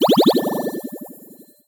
potion_bubble_effect_brew_03.wav